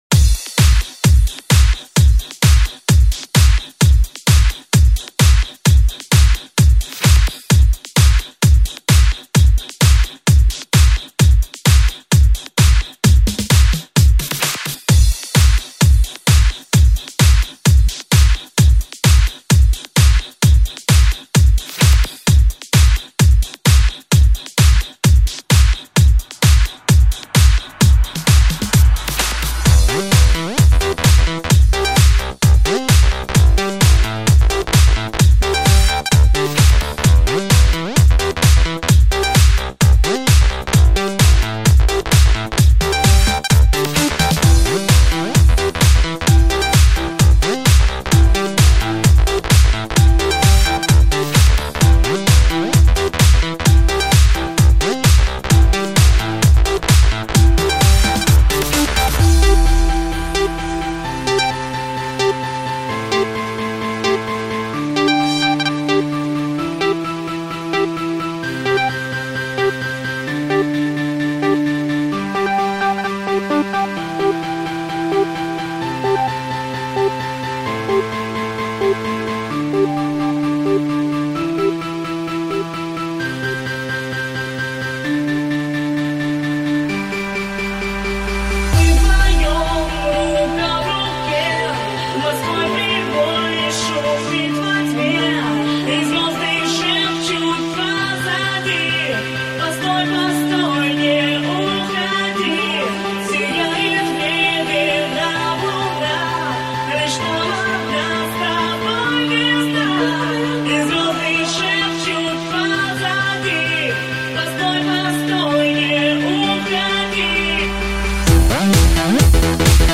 Жанр:Electro/House